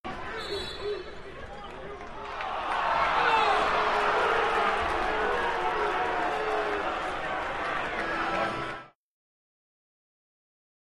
Basketball, Game, Crowd Angry At Call, Light Boos And Yells.